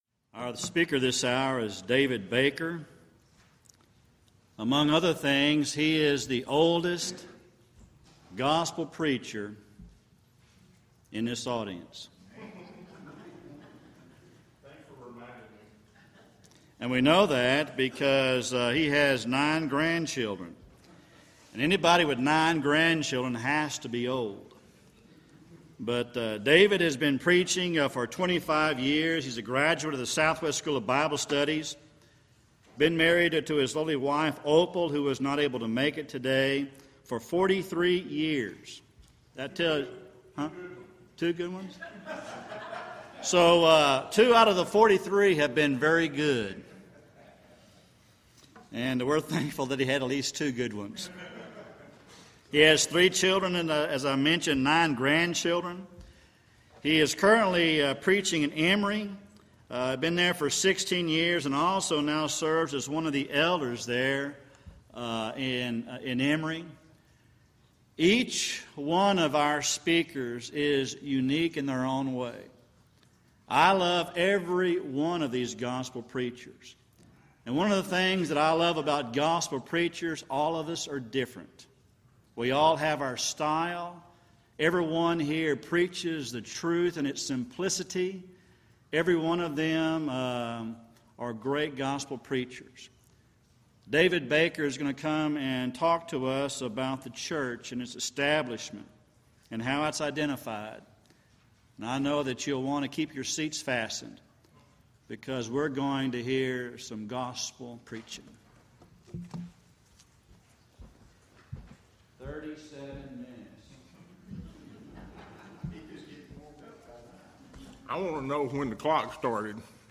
Series: Back to the Bible Lectures Event: 3rd Annual Back to the Bible Lectures